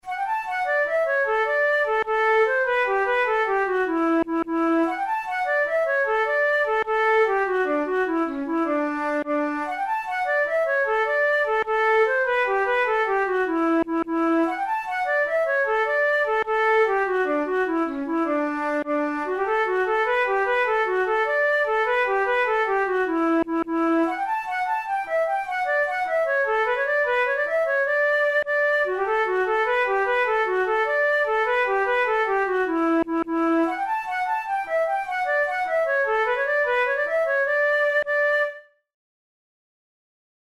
InstrumentationFlute solo
KeyD major
Time signature6/8
Tempo100 BPM
Jigs, Traditional/Folk
Traditional Scottish jig